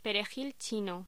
Locución: Perejil chino
voz